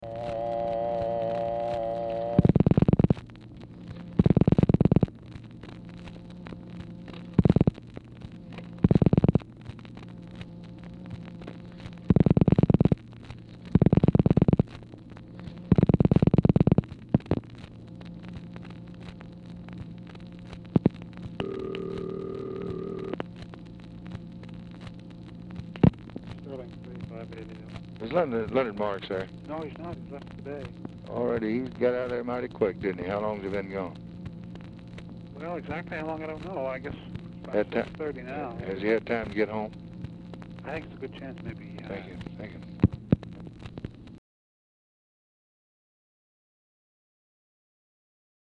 UNIDENTIFIED MALE
Oval Office or unknown location
RECORDING BEGINS WITH DIAL TONE, NUMBER BEING DIALED
Telephone conversation
Dictation belt